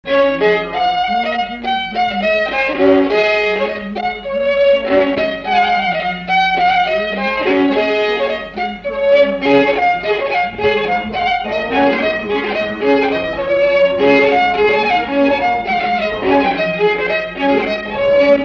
Mémoires et Patrimoines vivants - RaddO est une base de données d'archives iconographiques et sonores.
Chants brefs - A danser
Répertoire du violoneux
Pièce musicale inédite